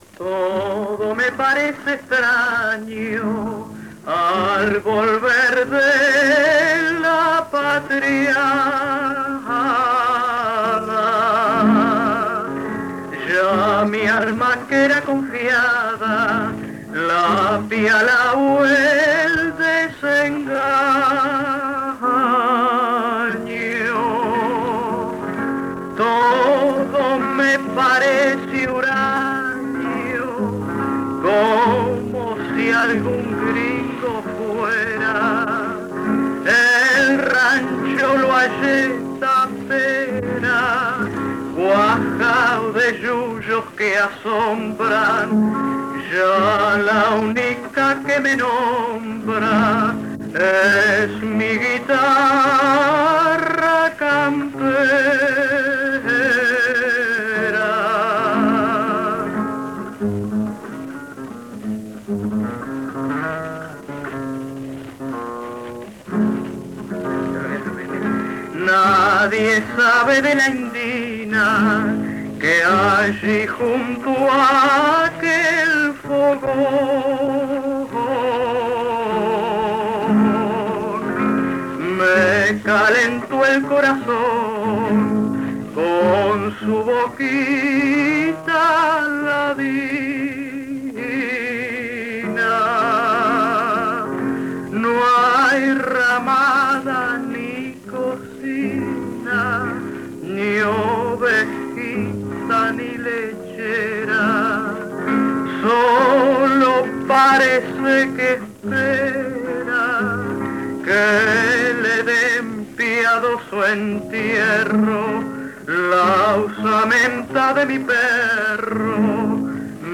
Ejecutante, edad, instrumental: Amalia de la Vega, 35 años, canto
Formato original de la grabación: disco de acetato Audiodisc de 25 cm de base metálica a 78 rpm